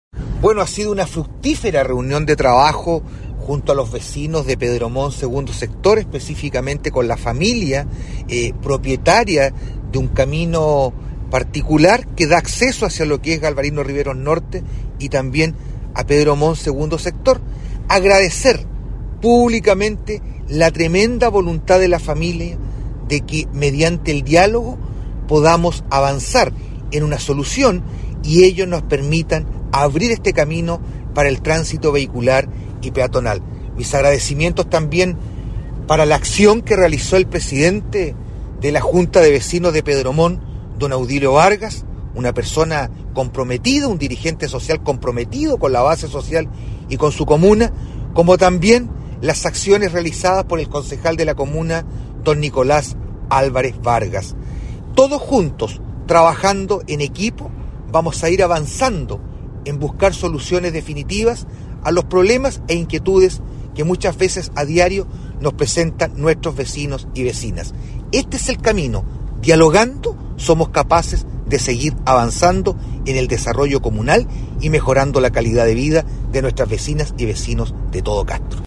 ALCALDE-VERA-AUDIO.mp3